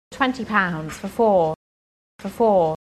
A good way to illustrate this is with real utterances in which native speakers say the words for four in sequence: /fə fɔː/ or /f fɔː/. Notice the weakness of for and the prominence of four: